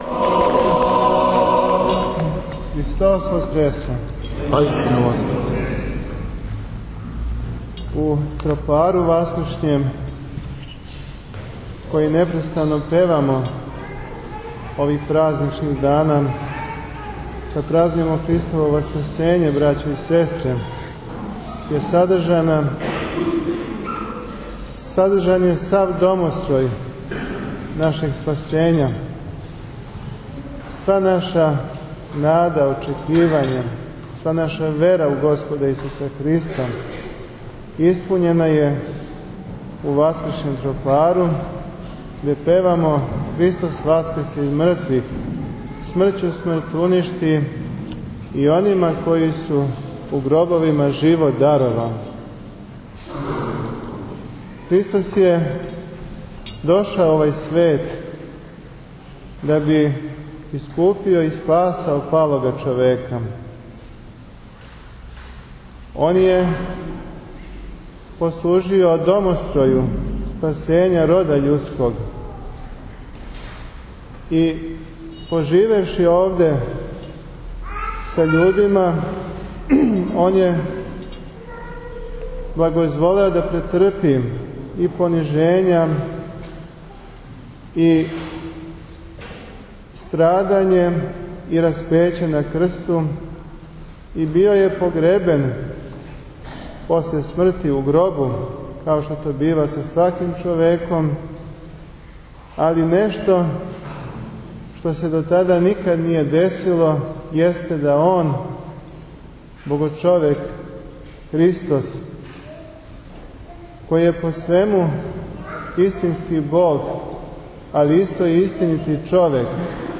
This text will be replaced Беседа Епископа Теодосија